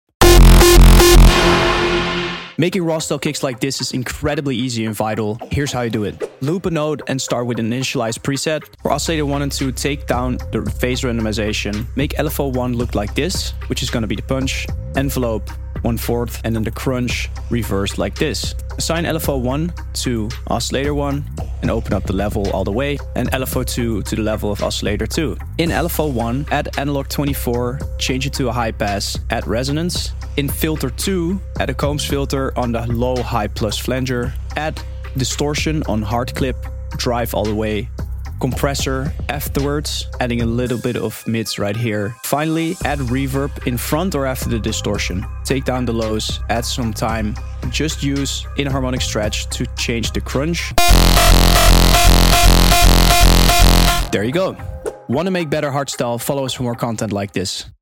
Rawstyle & Hardstyle Kicks In Sound Effects Free Download